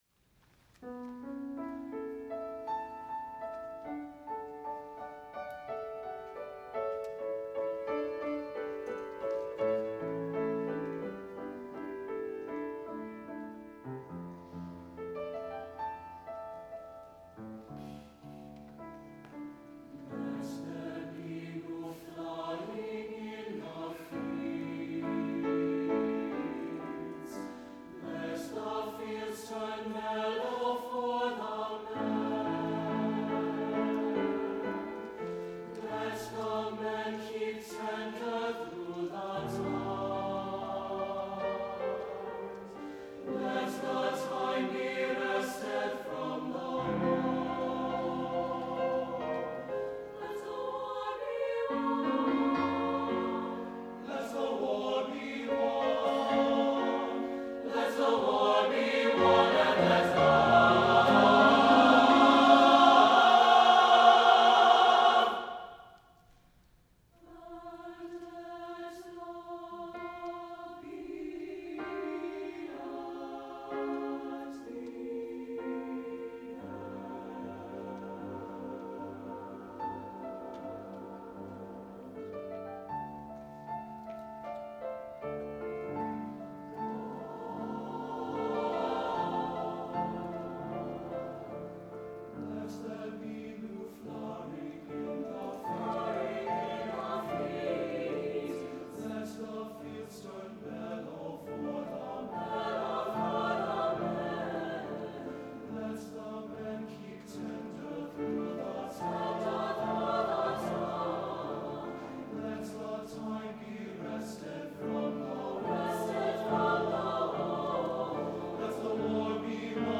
for SATB Chorus and Piano* (or Strings) (1992)